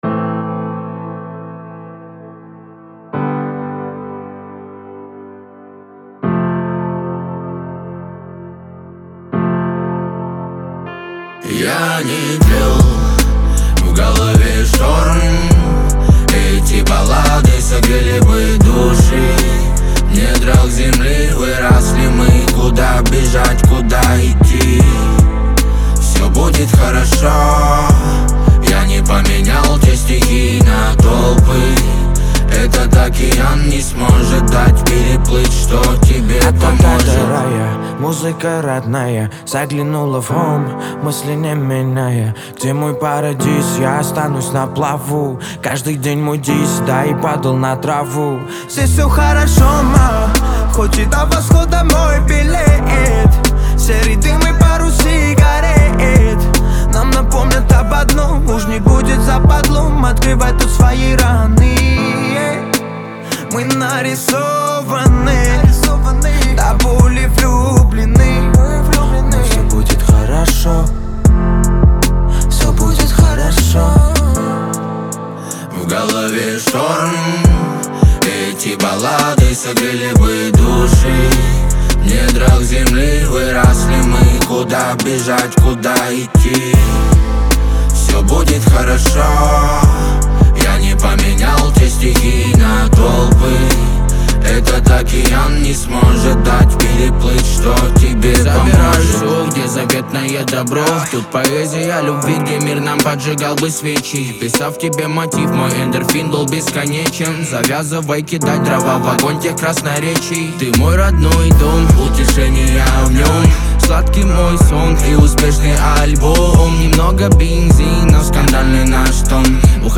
это энергичная и оптимистичная композиция в жанре хип-хоп